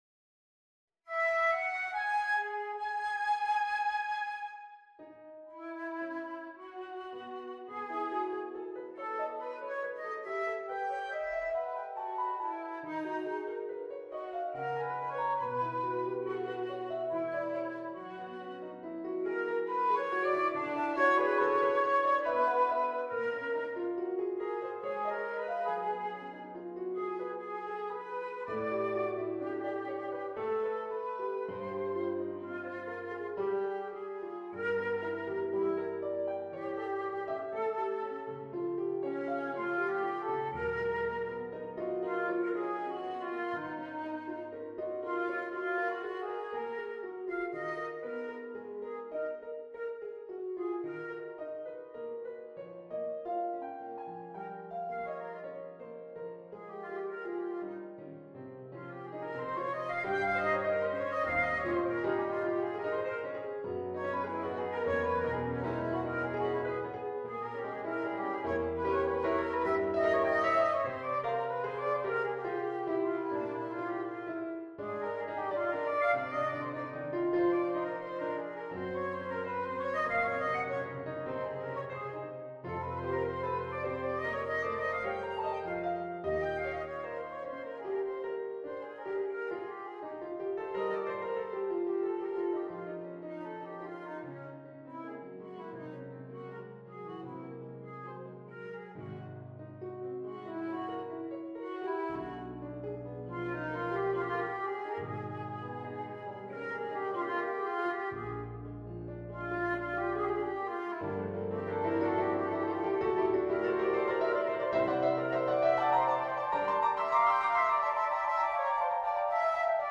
Hello, I wanted to share this new piece I'm working on. As the title says, it's about impressions of summer winds and breezes, for flute and piano.